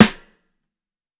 Sucker Snare.wav